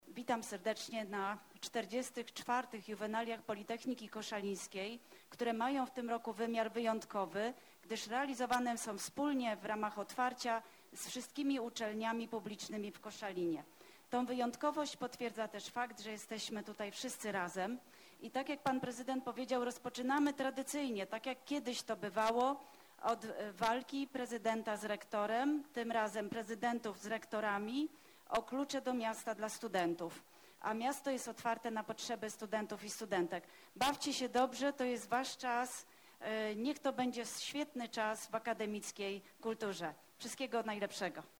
Wystartowały koszalińskie Juwenalia. Zgromadzonych na placu pod ratuszem gości powitała Danuta Zawadzka, rektor Politechniki Koszalińskiej.
Prof. Danuta Zawadzka – rozpoczęcie Juwenaliów